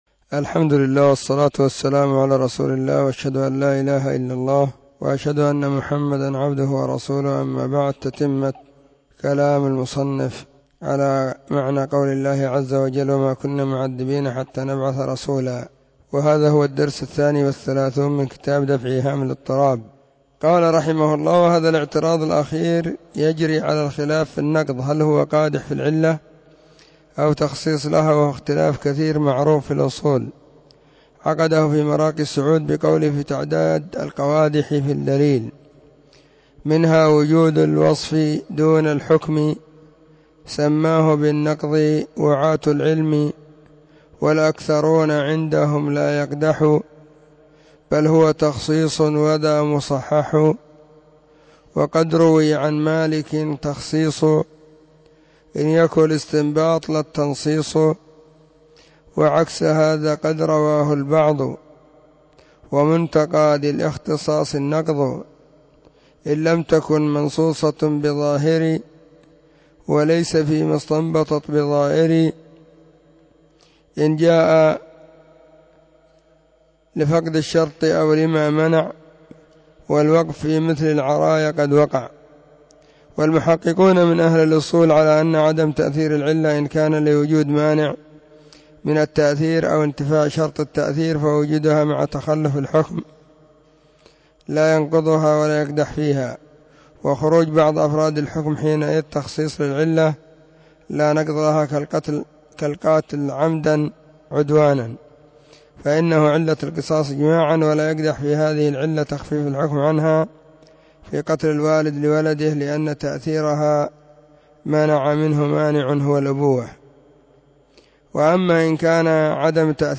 ⏱ [بعد صلاة الظهر في كل يوم الخميس]
📢 مسجد الصحابة – بالغيضة – المهرة، اليمن حرسها الله.